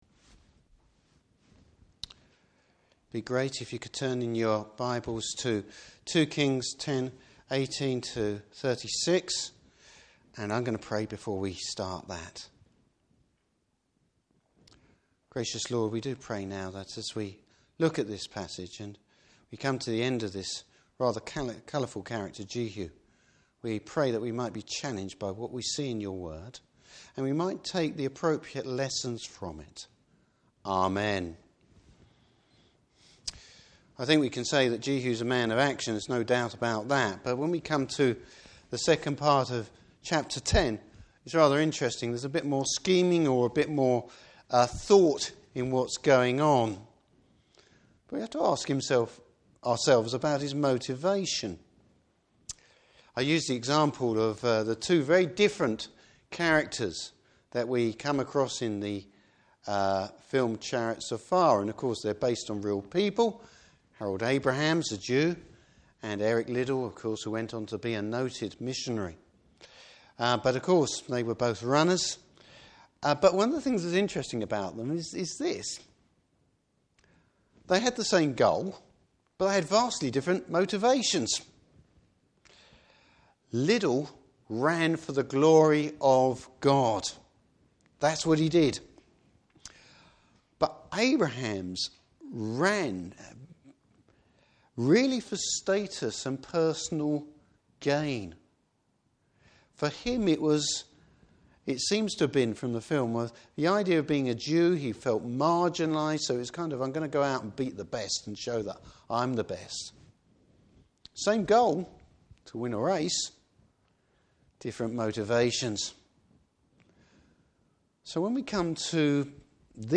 Service Type: Evening Service Bible Text: 2 Kings 10:18-36.